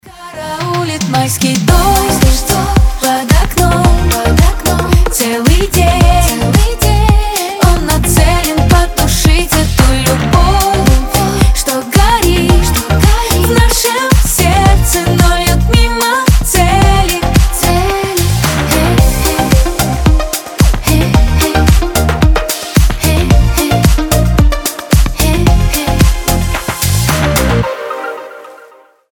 • Качество: 320, Stereo
позитивные
легкие